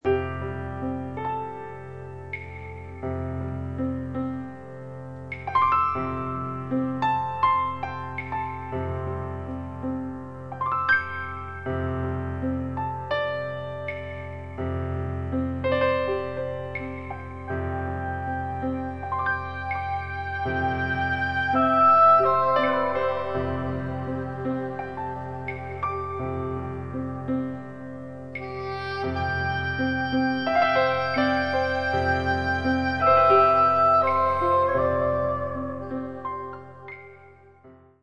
(Solo Piano and Ensemble)
soprano saxophone
oboe
fluegelhorn